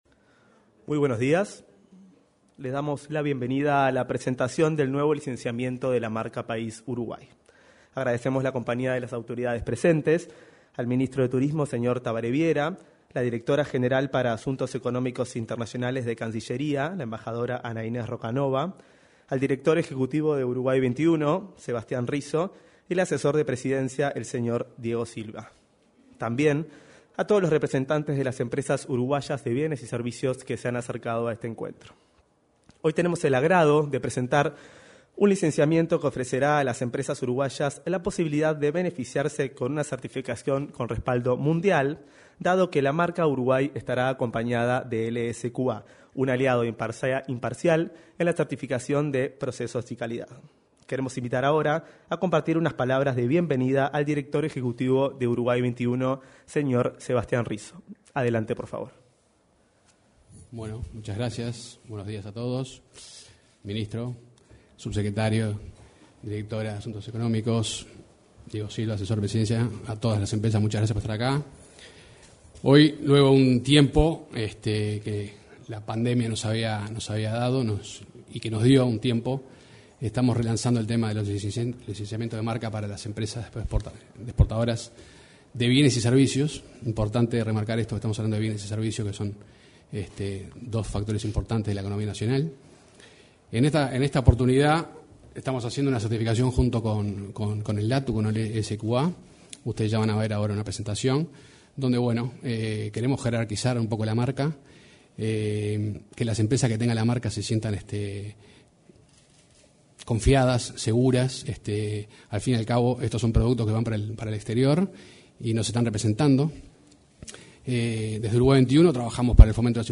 Este miércoles 12, en el salón de actos de la Torre Ejecutiva, se realizó la presentación del nuevo licenciamiento de la marca país Uruguay.